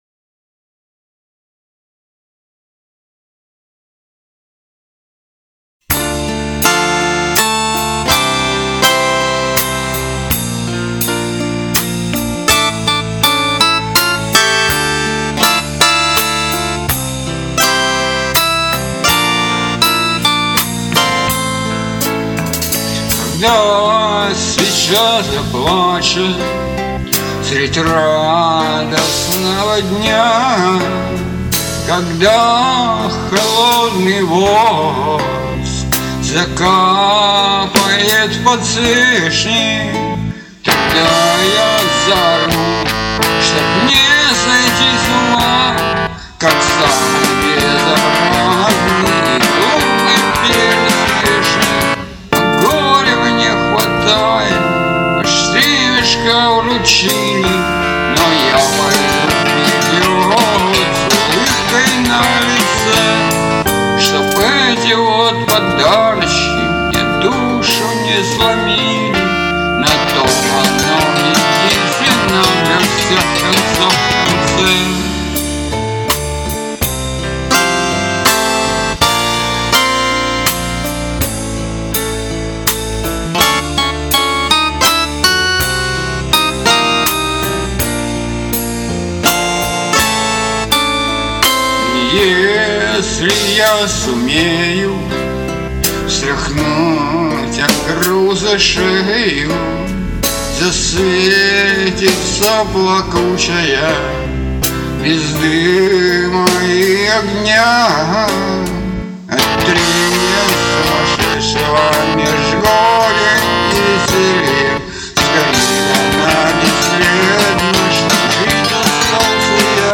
Музыкальный хостинг: /Бардрок